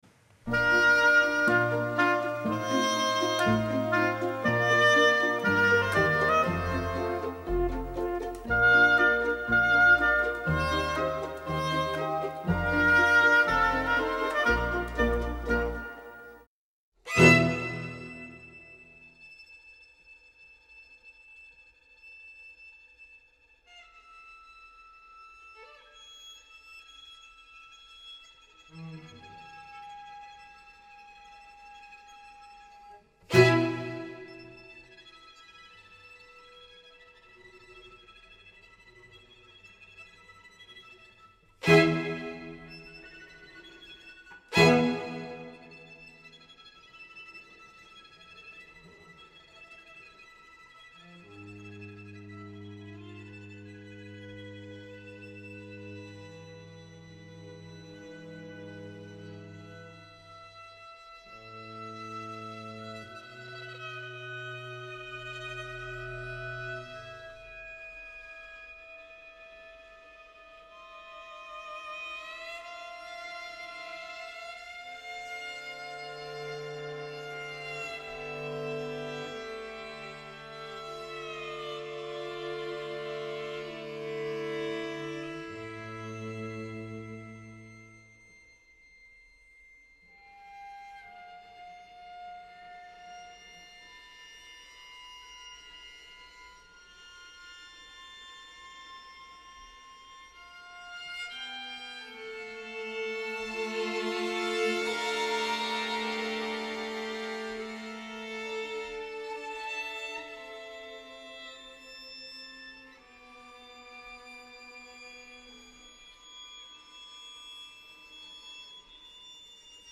Due voci importanti della composizione internazionale che portano avanti un messaggio per le compositrici del futuro: sentiremo le loro opinioni sulla musica contemporanea d’oggi